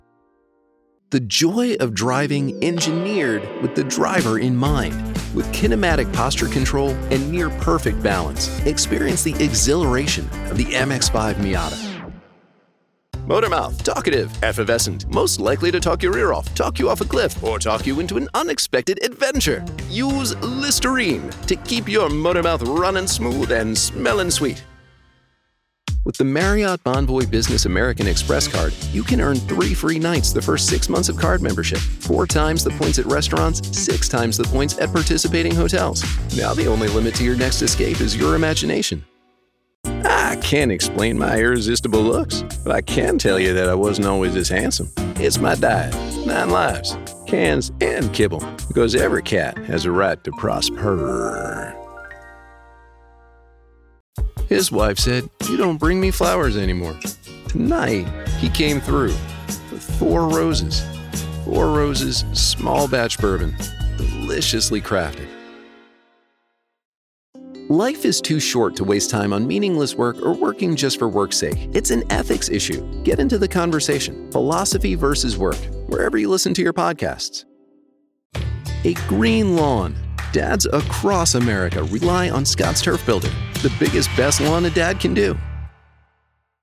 Articulate, intelligent, conversational. Your favorite, laid-back college professor.
Commercial Demo 2024
US Midatlantic, US Southeast, US Northeast
Middle Aged